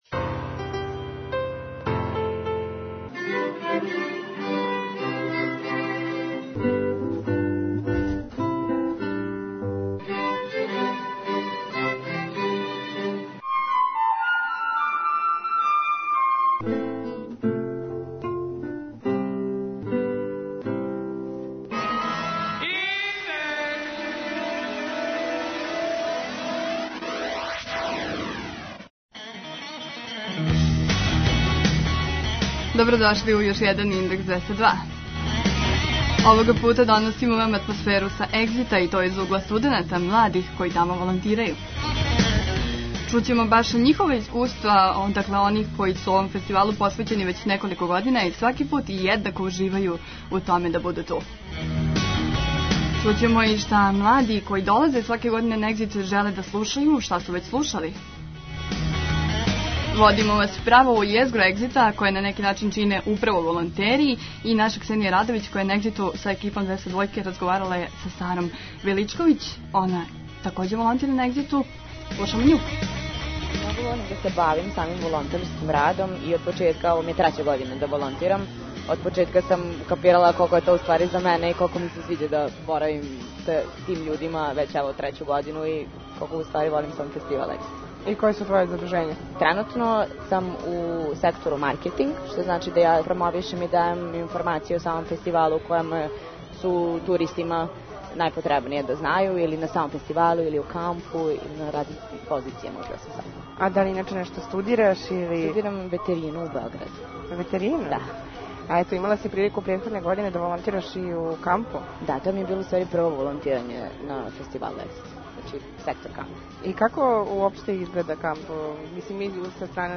Доносимо вам атмосферу са Егзита из угла студената.